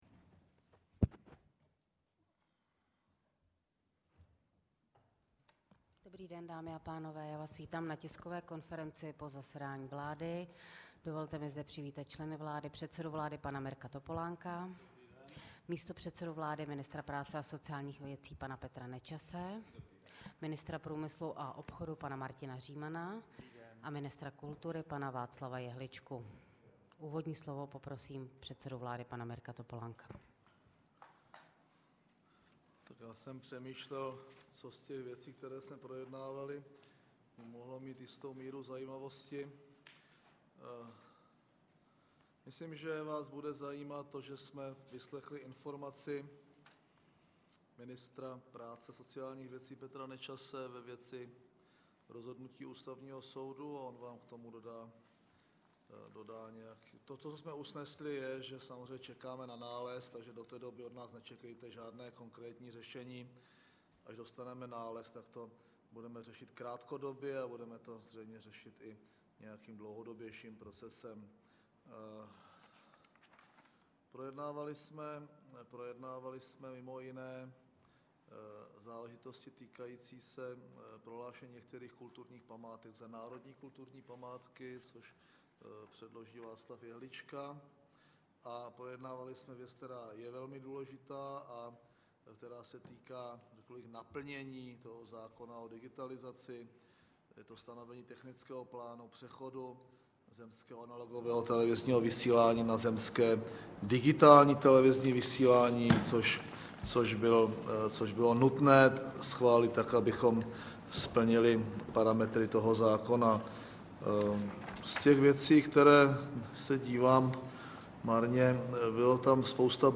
Tisková konference po jednání vlády ČR 28. dubna 2008